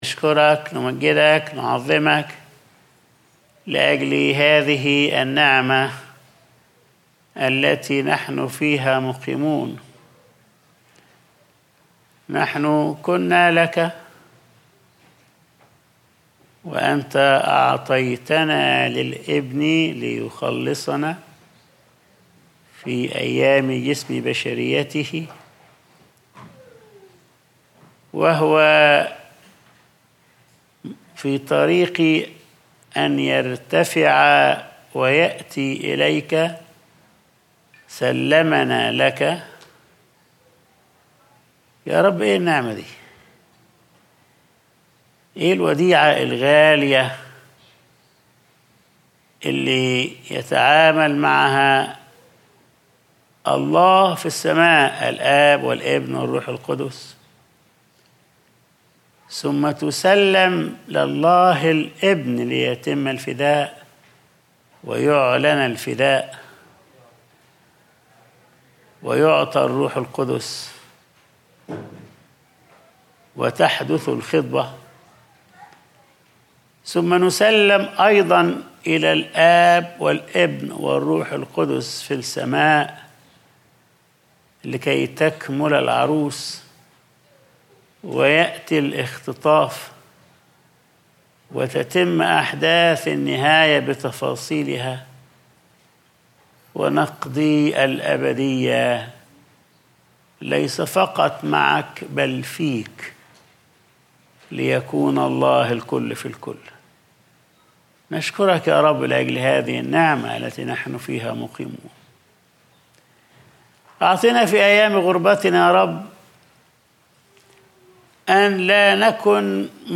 Sunday Service | هذه هي الحياة الأبدية